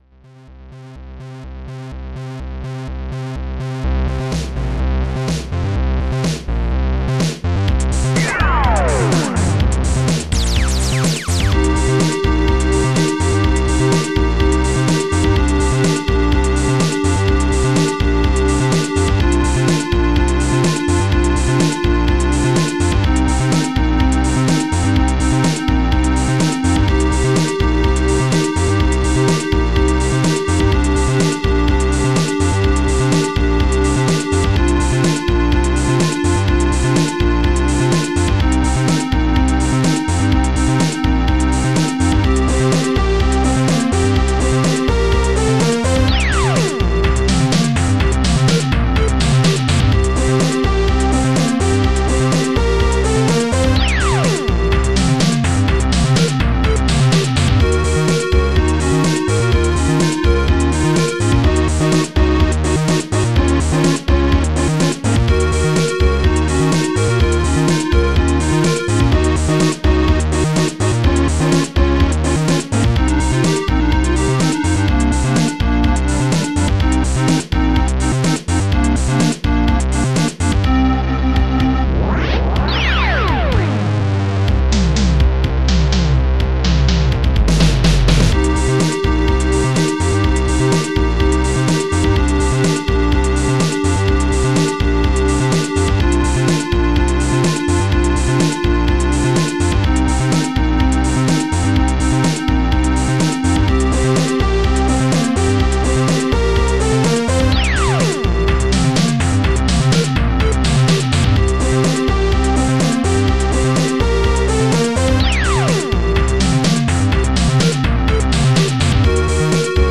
Protracker and family
st-10:fatstring
st-10:snaredrum
st-10:bassdrum
st-10:squarewave
st-10:fatlead
st-10:gunfire1